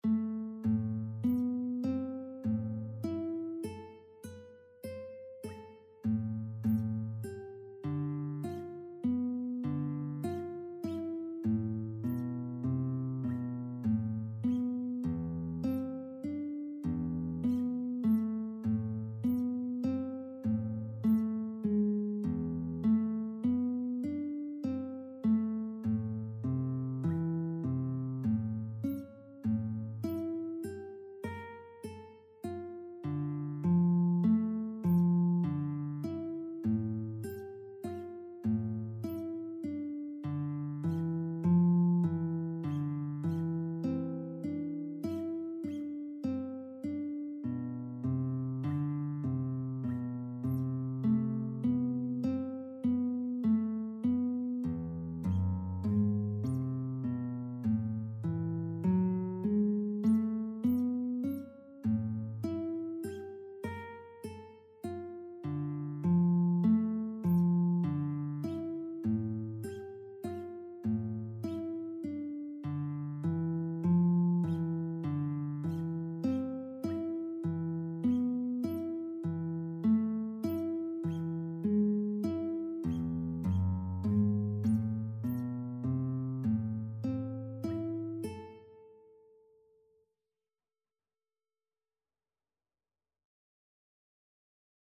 Guitar (TAB)
3/4 (View more 3/4 Music)
E3-C6
Classical (View more Classical Guitar Music)